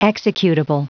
Prononciation du mot executable en anglais (fichier audio)
Prononciation du mot : executable